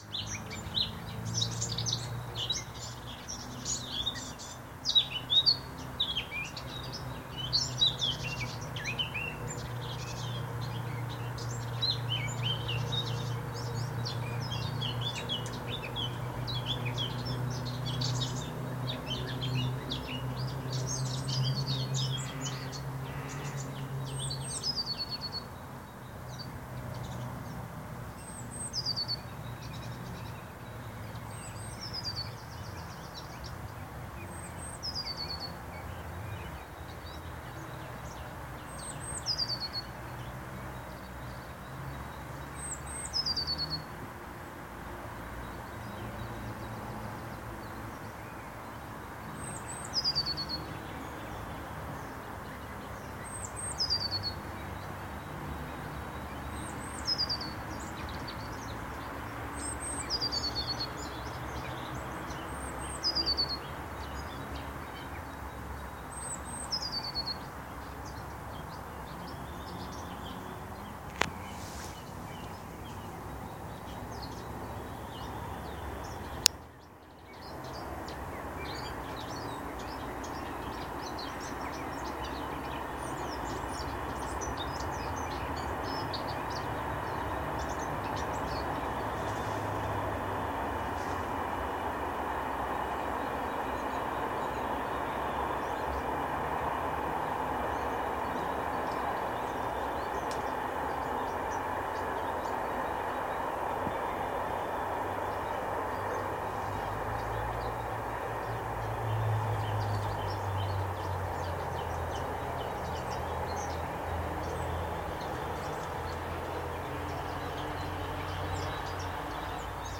自然 " 森林鸟类 2
描述：在森林里唱歌的鸟的野外记录。用Zoom H1记录
标签： 鸟鸣声 氛围 环境 森林 鸟类 气氛 春天 自然 现场录音
声道立体声